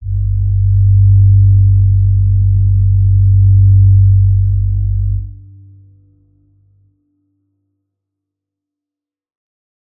G_Crystal-G2-f.wav